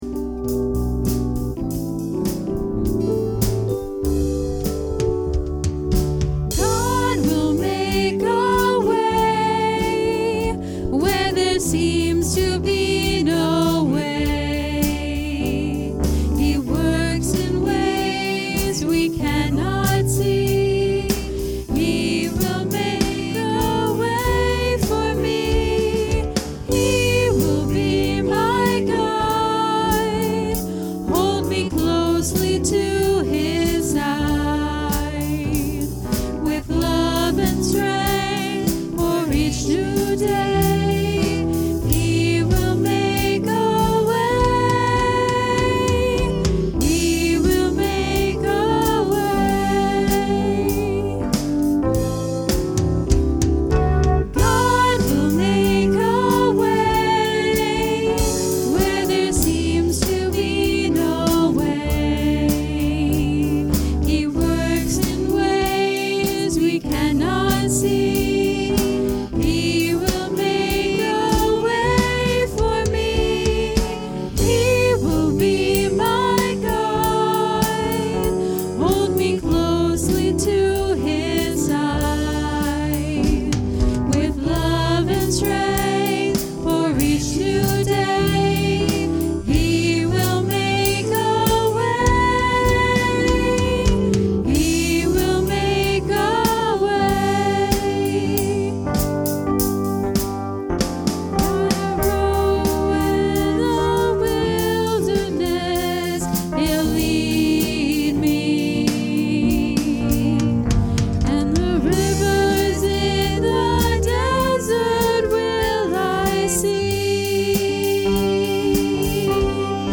Acts 20:22-38 Service Type: Sunday Morning Worship What grade would our give our culture and society on the value of loyalty?